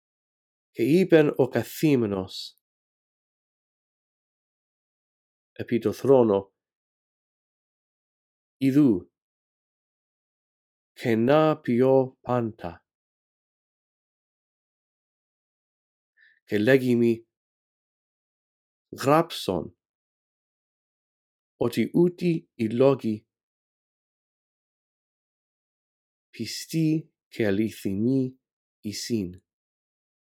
In this audio track, I read through verse 5 a phrase at a time, giving you time to repeat after me. After two run-throughs, the phrases that you are to repeat become longer.